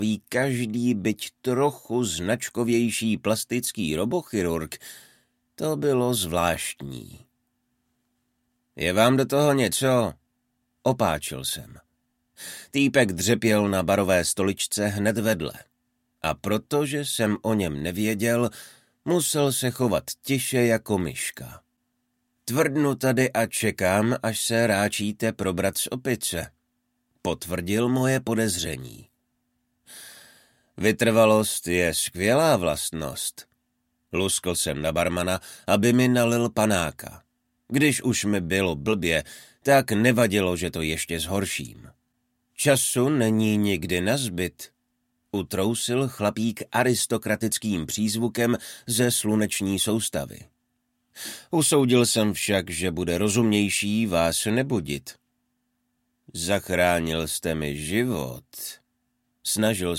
Audiobook
Audiobooks » Best Sellers, Fantasy & Sci-fi